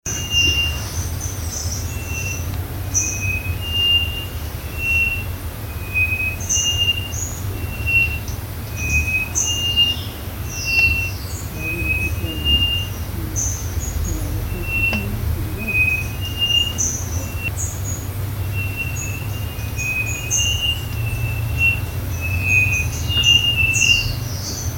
Chochín Pechicastaño (Cyphorhinus thoracicus)
Voz-059-cyphorrinus-toracicus-la-florida-7-4-23.mp3
Nombre en inglés: Southern Chestnut-breasted Wren
Localidad o área protegida: Finca la florida
Condición: Silvestre
Certeza: Vocalización Grabada